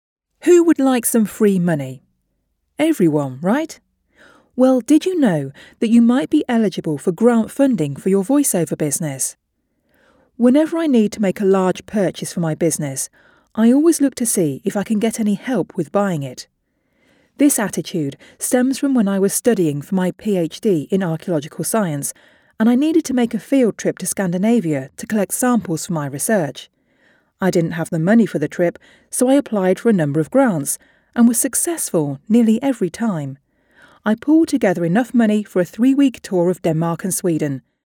Female
British English (Native)
Assured, Authoritative, Confident, Corporate, Engaging, Friendly, Gravitas, Natural, Reassuring, Smooth, Soft, Warm, Witty, Conversational
My voice can be described as confident and assured with a naturally soothing quantity.
Microphone: Neumann TLM103